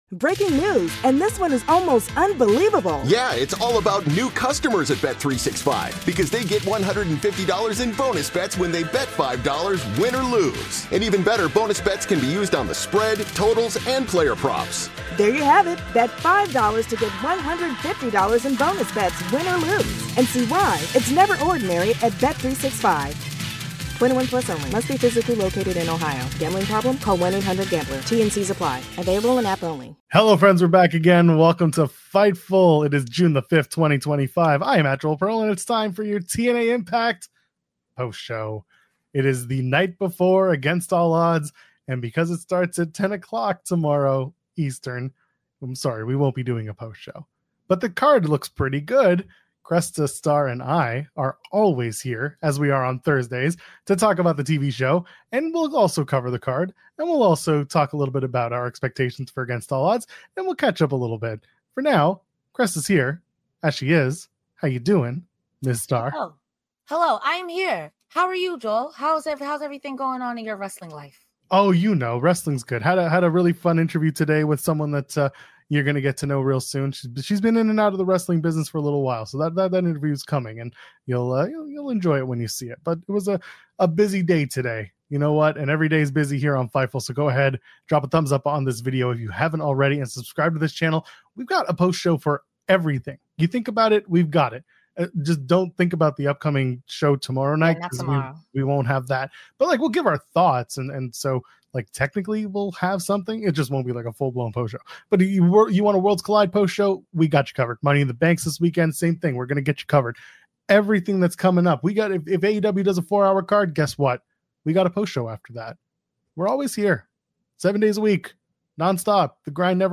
Download - Savio Vega On Steve Austin, Kevin Dunn, Brawl For All, MLW Battle Riot | 2021 Shoot Interview | Podbean